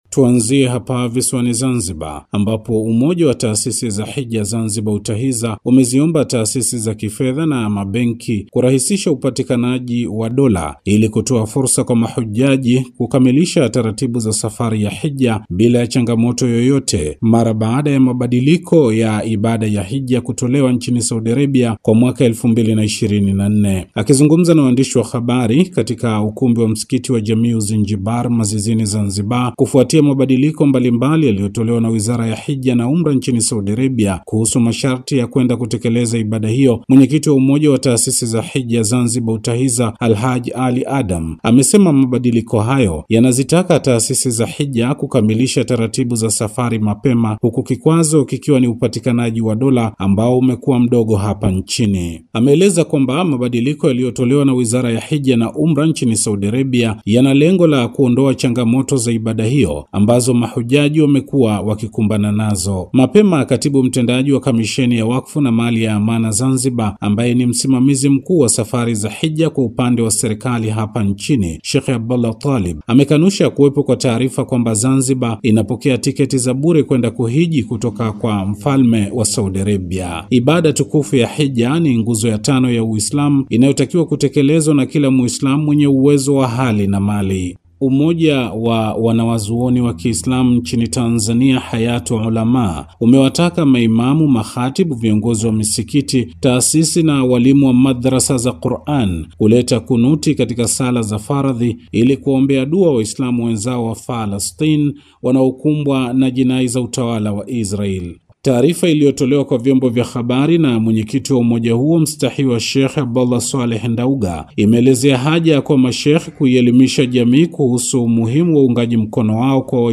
Ripoti ya Matukio ya Kiislamu Afrika Mashariki+SAUTI